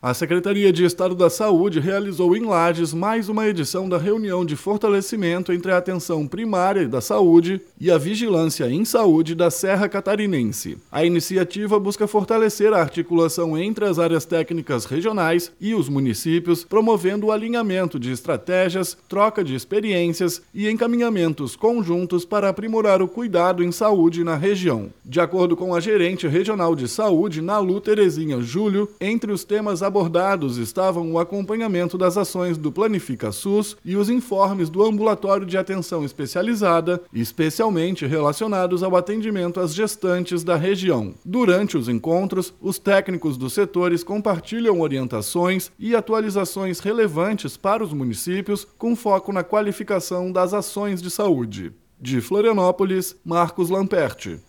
BOLETIM – Secretaria promove na Serra reunião de fortalecimento entre Atenção Primária e Vigilância em Saúde